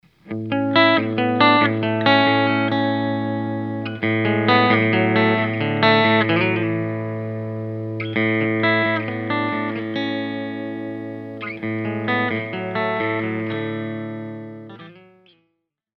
It has a pronounce midrange growl with slightly less high end and more bass.
As with all of our humbuckers, the Code 3 splits with minimal volume drop, producing beautiful single coil tones.
Recording Specs: Mahogany body, SM57, EVH 5150 III, 4×12 cab with Celestion V30’s
code3_clean_split.mp3